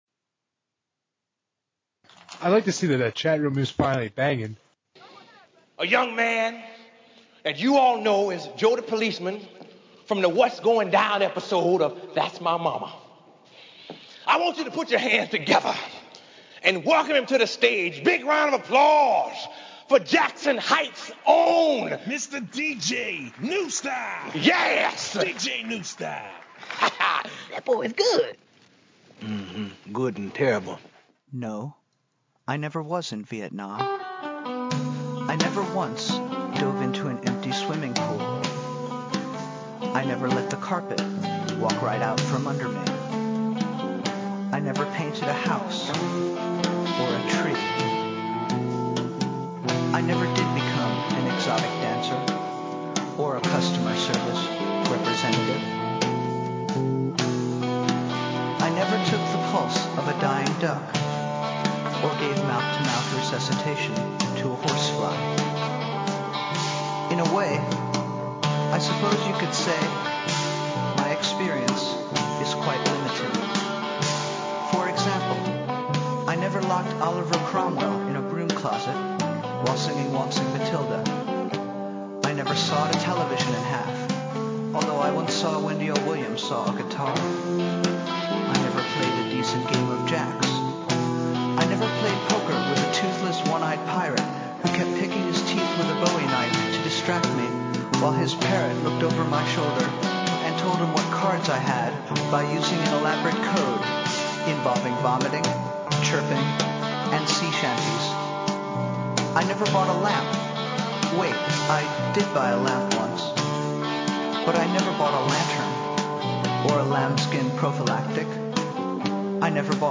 Originally broadcast October 2, 2007. I’m sick and my voice hurts due to a cold that a half Chinese, half Polish, fedora wearing co-worker has been passing around the office.
It would have been better if my esophagus wasn’t constructed with sandpaper and phlegm.